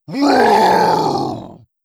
Monster Roars
01. Primal Roar.wav